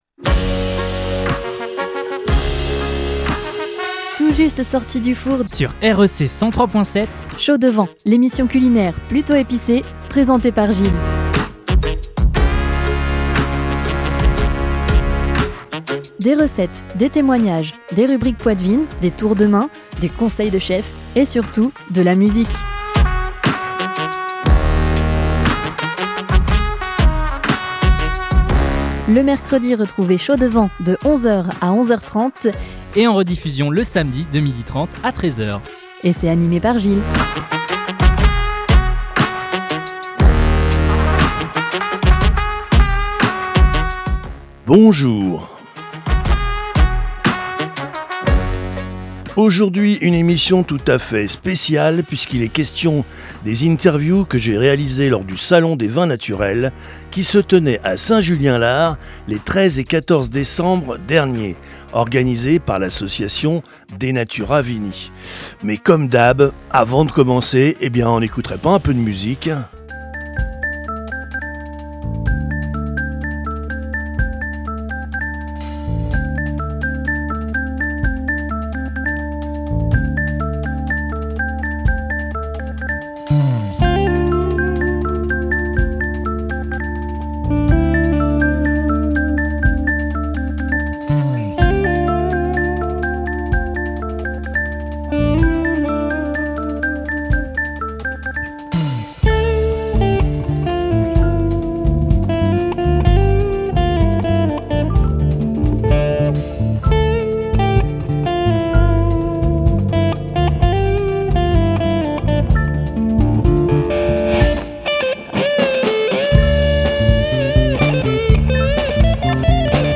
Aujourd’hui en début d’émission vous ne pourrez pas échapper , à une émission spéciale , puisque aujourd’hui je m’en vais diffuser les interviews , que j’avais réalisé lors du salon des vins naturels de Saint Julien Lars , les 13 et 14 décembre dernier organisé par l’association DE NATURA VINI . Effectivement vous retrouverez les interviews : d’une productrice de Poiré , d’un paysan fromager et d’une viticultrice des coteaux du Thouarsais .